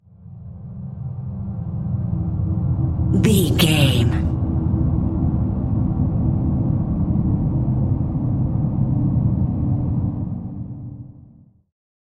Sound Effects
Atonal
scary
tension
ominous
dark
haunting
eerie
creepy
synth
ambience
pads